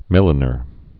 (mĭlə-nər)